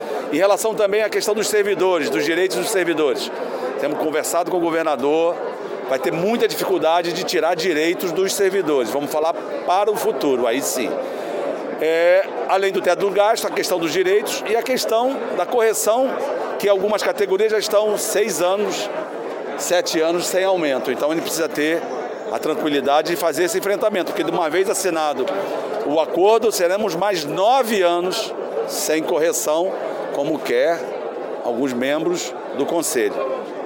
O presidente da Alerj, André Ceciliano, em entrevista coletiva, disse prever dificuldades para aprovar o novo Regime de Recuperação Fiscal que vai ser votado agora, no segundo semestre.  Uma das questões problemáticas é a que trata do reajuste dos servidores.